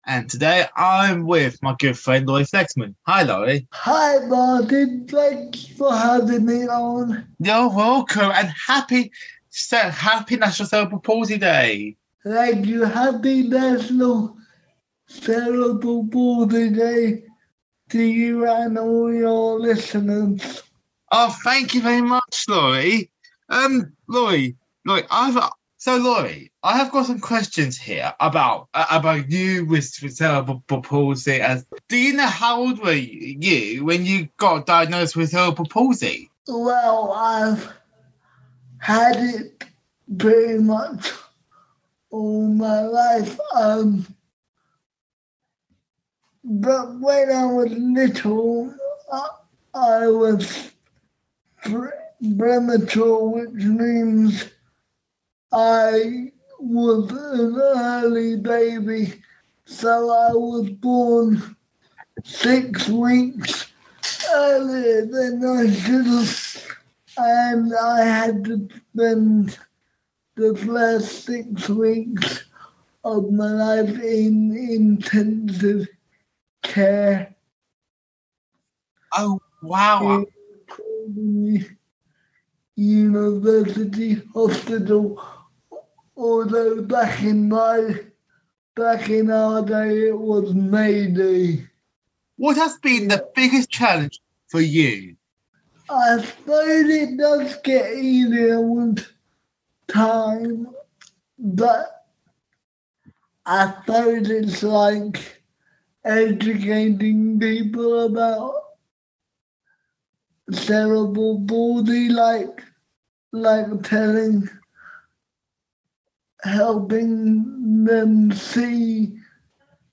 To mark World Cerebral Palsy Day on 6th October, we would like to share an interview that was played on our inclusive radio show, Sunny Sessions Extra, earlier this week.